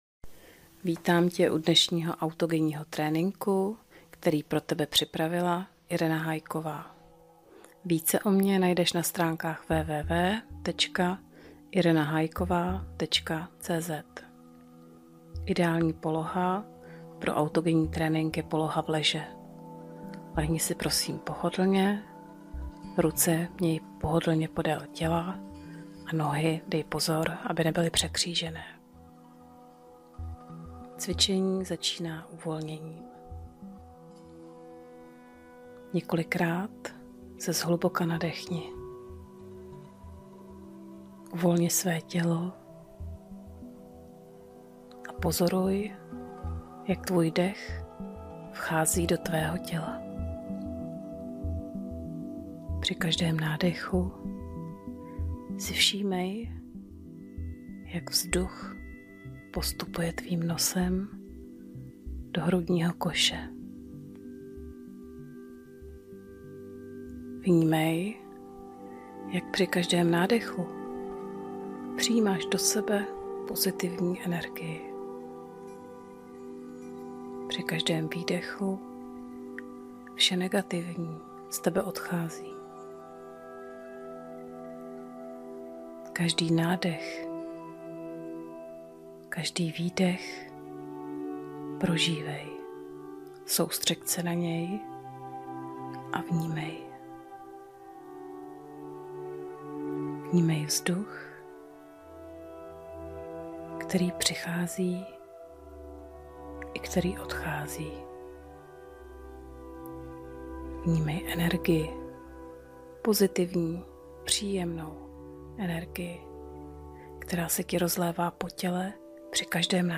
Autogenní trénink-řízená relaxace-zvukový soubor
autogenni-trenink.mp3